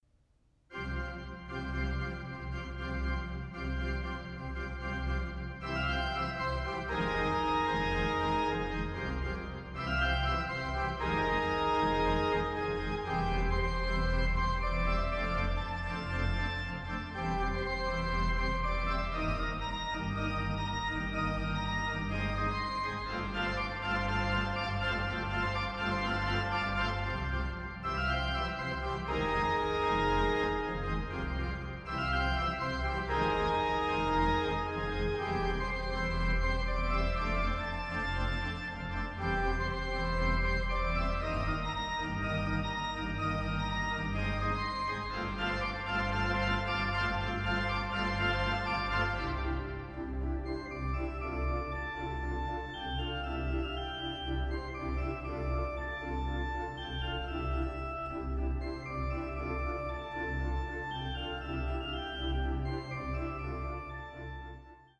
Sound Extracts From the Rieger Organ
The Rieger Organ of Christchurch Town Hall, New Zealand
Recorded in Christchurch Town Hall 29-31 January 2010.